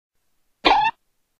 pointer_hanna_barbera_SFX
Tags: funny